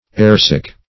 Airsick \Air"sick`\, a.